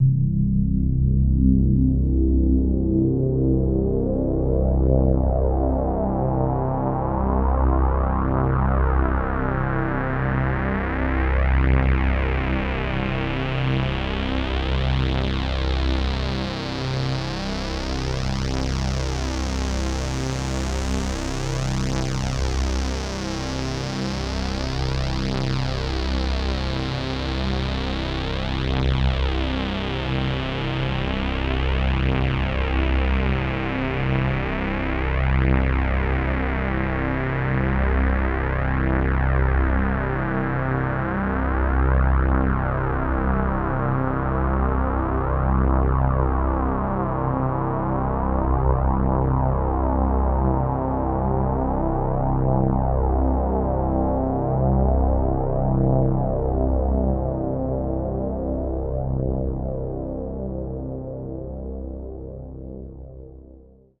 Index of /90_sSampleCDs/Club_Techno/Sweeps
Sweep_2_C2.wav